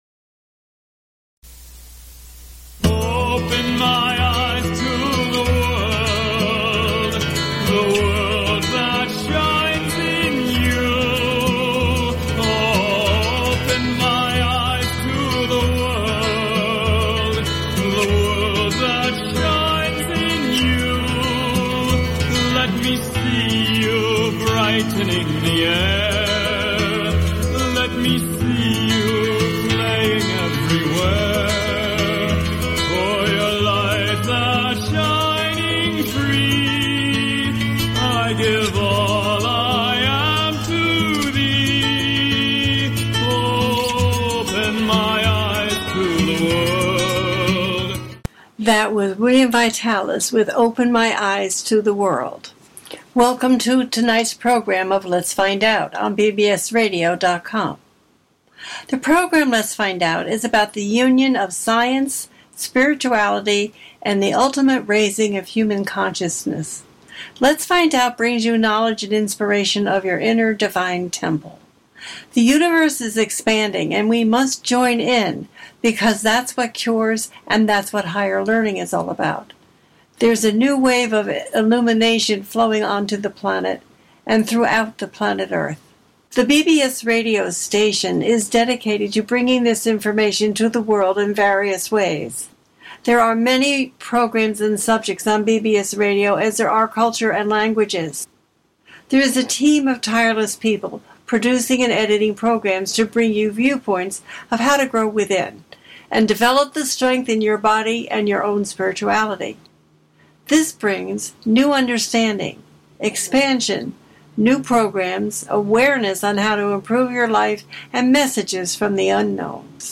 Support my show $2.99/mo or $5.99/mo or $9.99/mo Click HERE SUBSCRIBE TO TALK SHOW